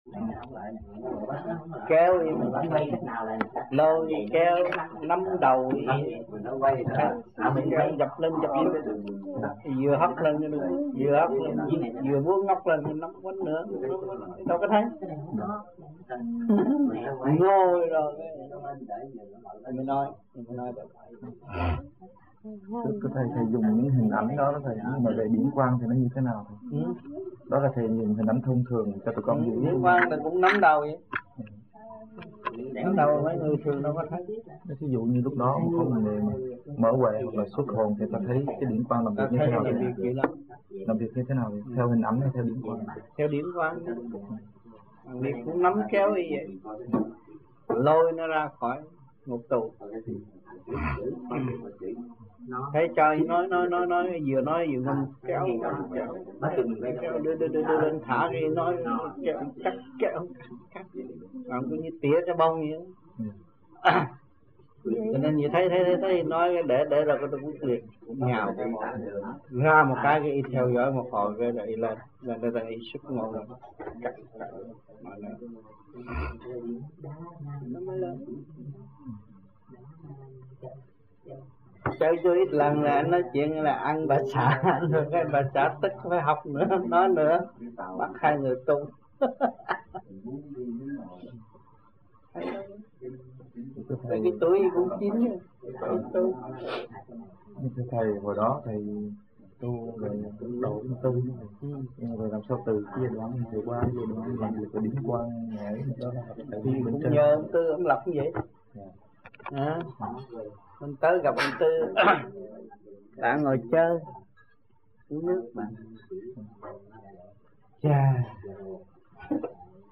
1980-11-11 - NANTERRE - THUYẾT PHÁP 4